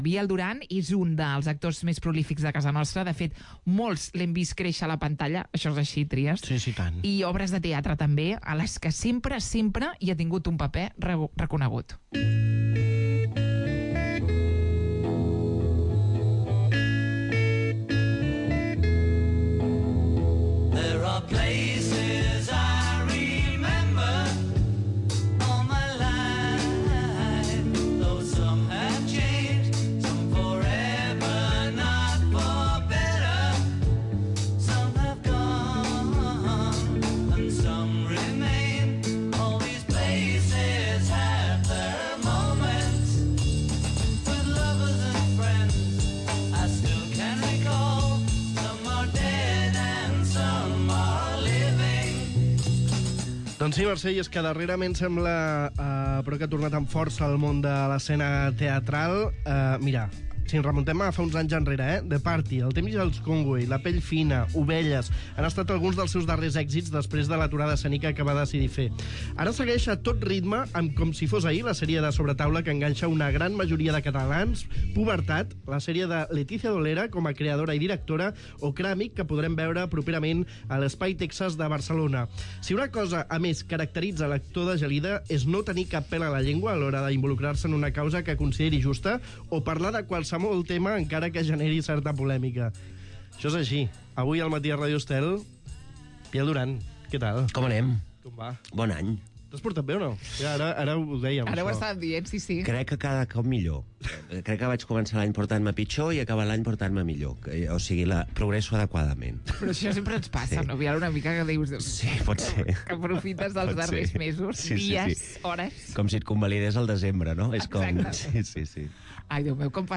Escolta l'entrevista a l'actor Biel Duran